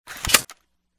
slide.wav